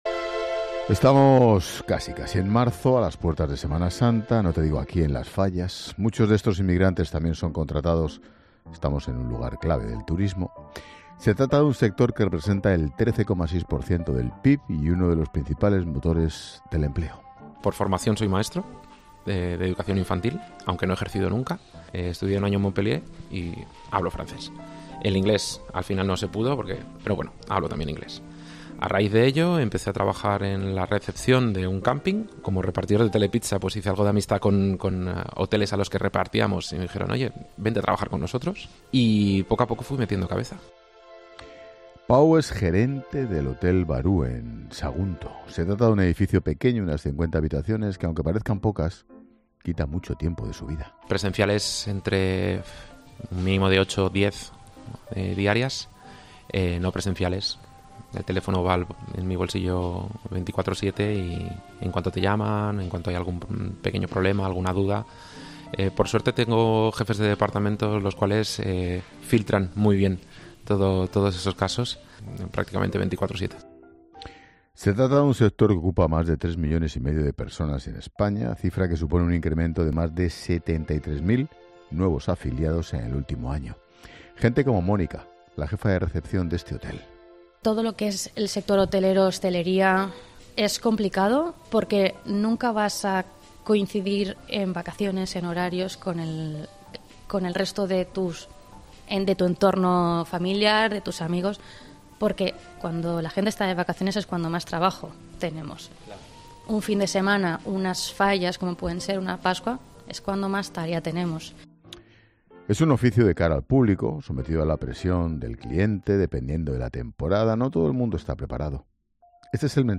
El programa 'La Linterna' de Expósito ha viajado a Valencia, a las puertas de las Fallas y la Semana Santa, para conocer de primera mano cómo trabajamos en España. El foco se ha puesto en el turismo, un sector clave que representa el 13,6 % del PIB y da empleo a más de 3,5 millones de personas.